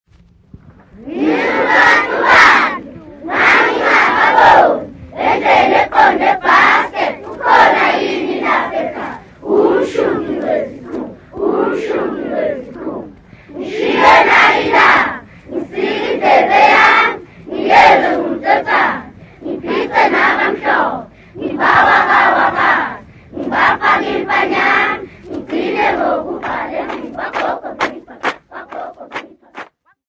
Maphophoma School Choir Nongoma
Folk music
Field recordings
sound recording-musical
Indigenous folk song with children's recital